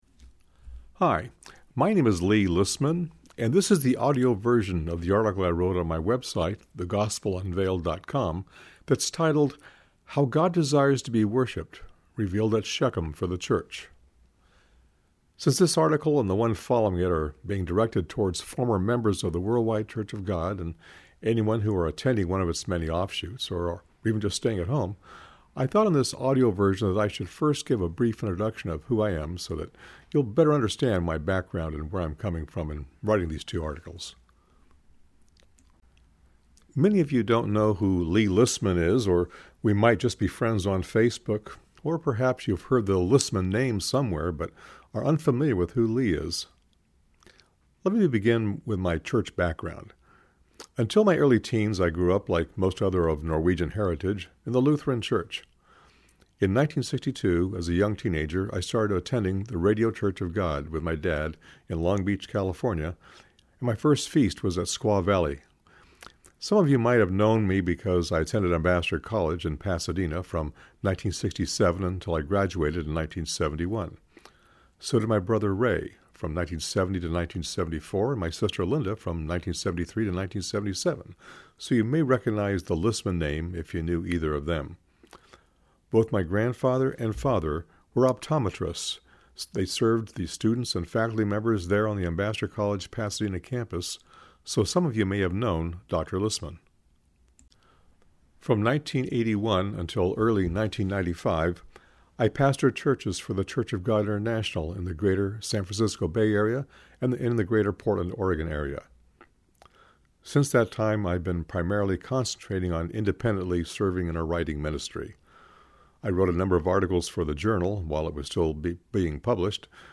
(Click Here if you wish to listen to the article read by myself)